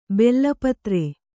♪ bella patre